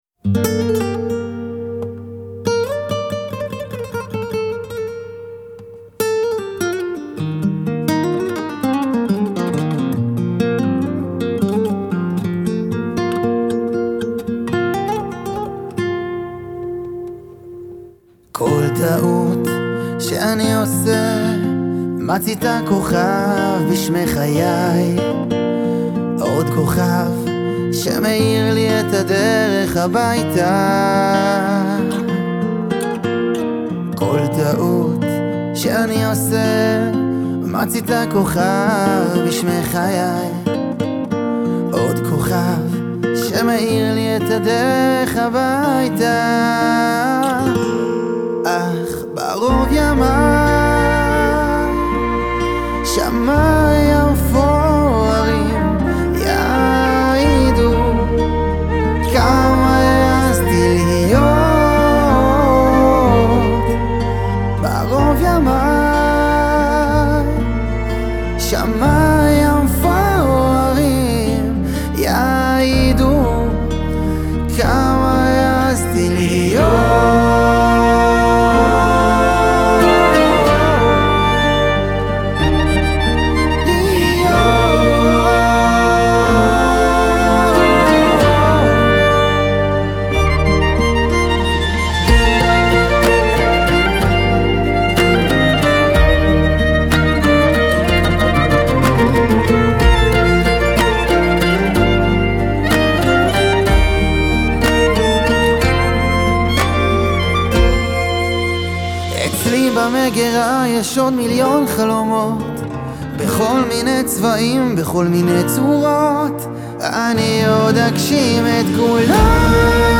דואט מרגש